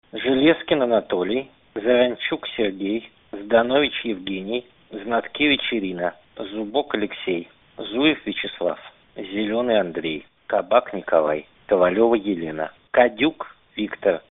Былыя прэзыдэнты, усясьветна вядомыя дысыдэнты, філосафы, парлямэнтары, міністры, пісьменьнкі, правабаронцы з Амэрыкі і Эўропы чытаюць імёны людзей, якія пацярпелі за дэмакратыю ў Беларусі пасьля 19 сьнежня 2010.